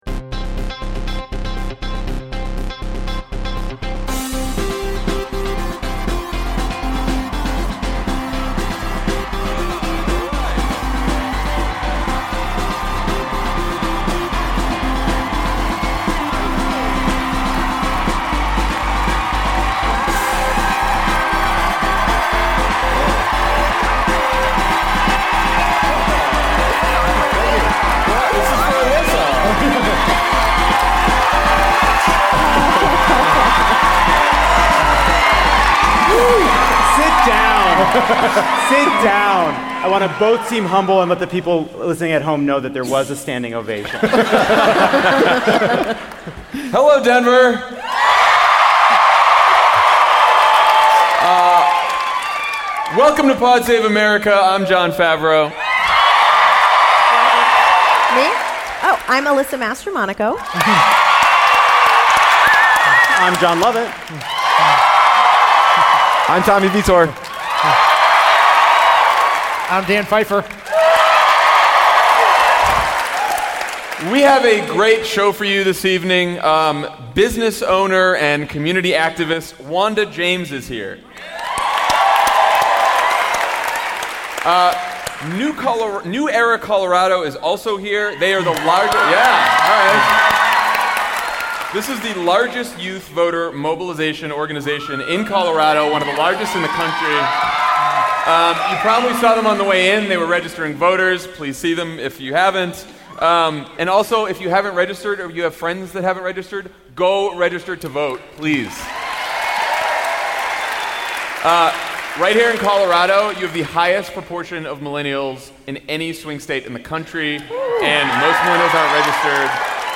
“A government eclipse.” (LIVE from Denver)